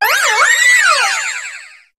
Cri de Diancie dans Pokémon HOME.